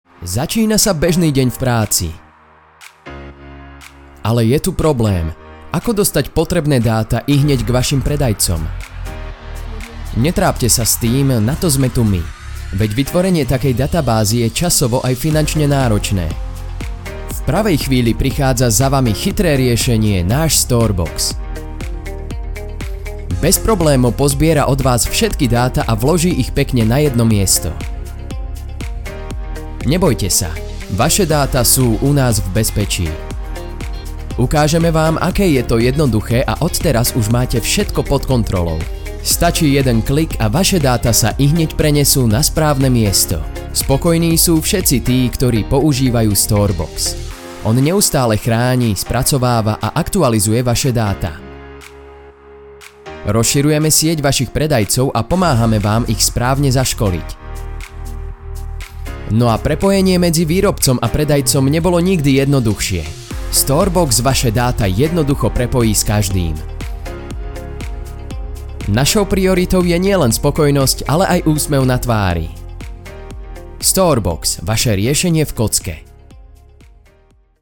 Profesinálny mužský VOICEOVER v slovenskom jazyku